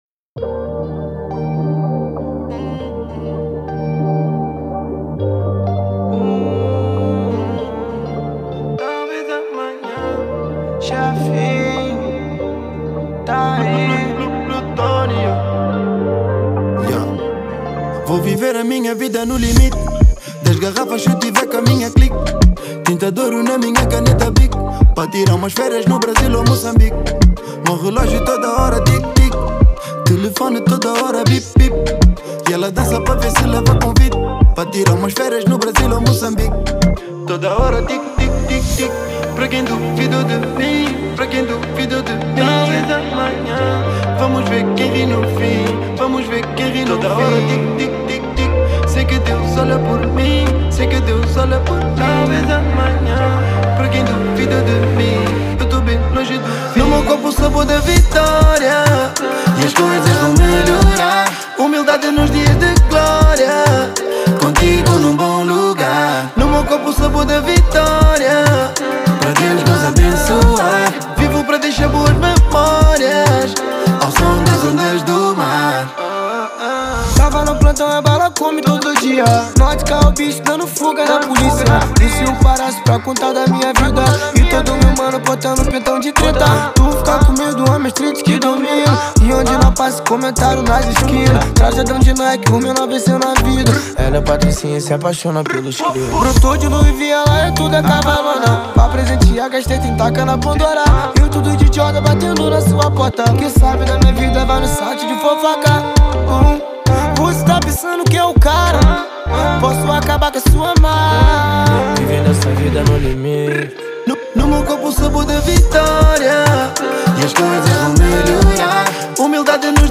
Genero: Trap